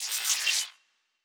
Sci-Fi Sounds / Electric
Data Calculating 4_2.wav